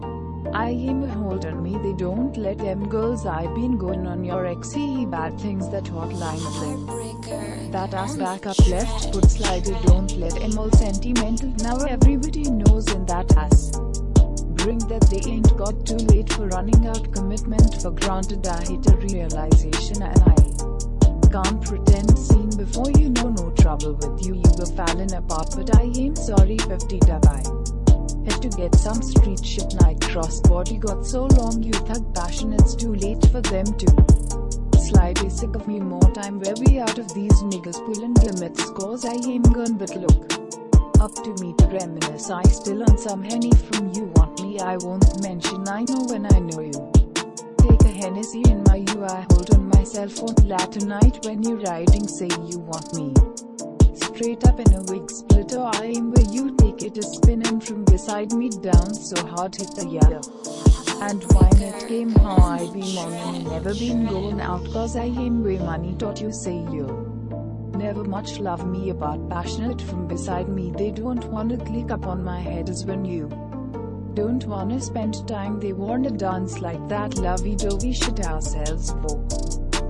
Mumble rap generator
Created a lyric generator using Markov-graph algorithm and overlayed it on generic rap beats.